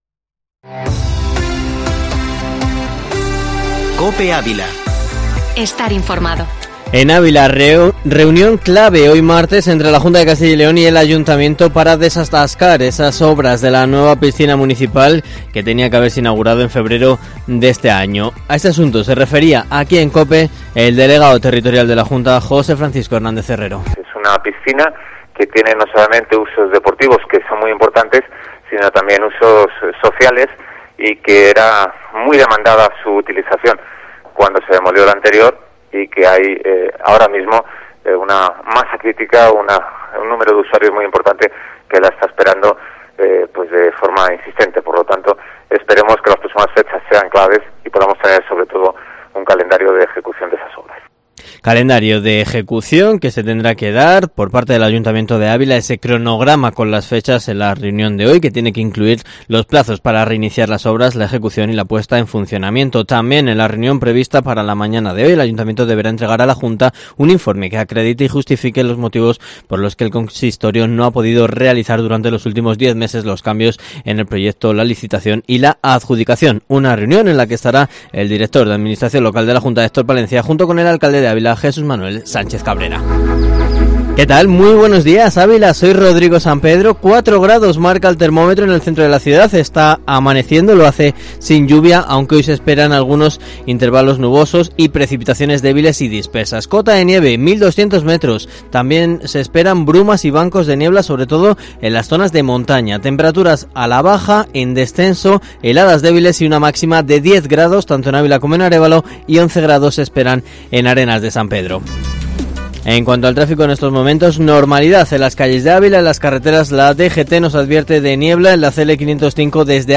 Informativo matinal Herrera en COPE Ávila 15/12/2020